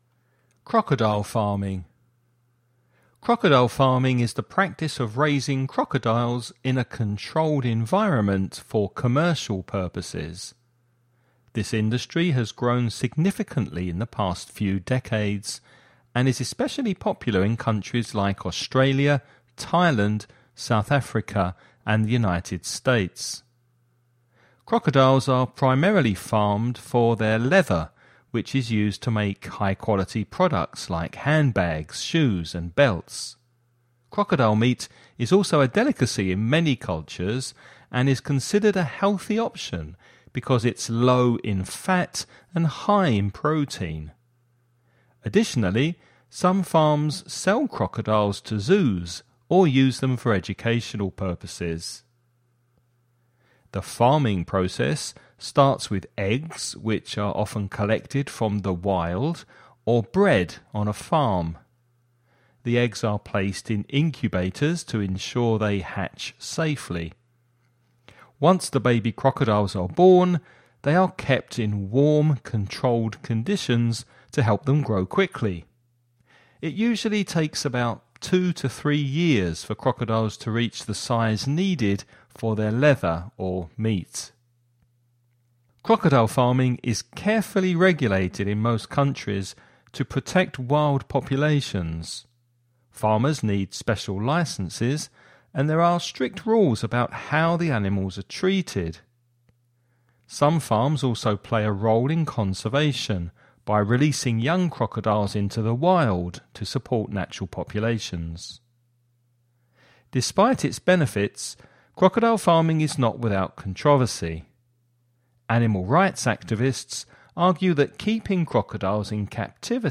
Listening Practice
You’re going to listen to a man talking about crocodile farming.